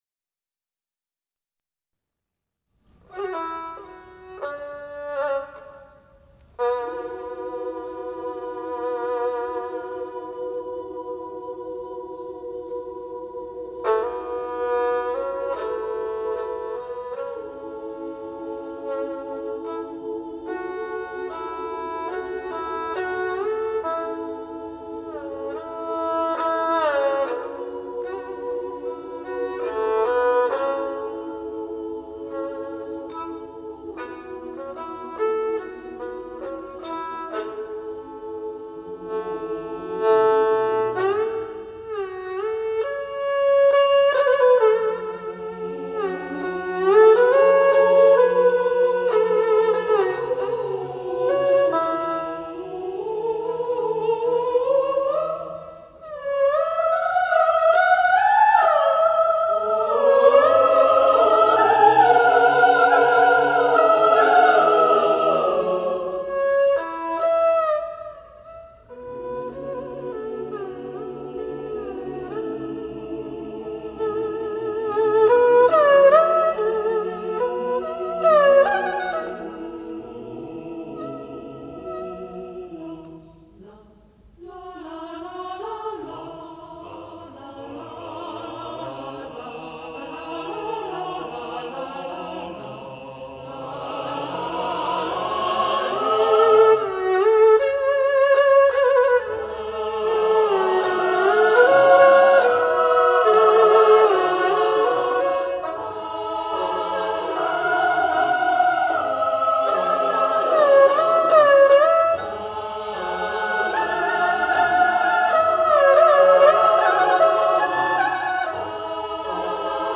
专辑风格：中国音乐
二胡与合唱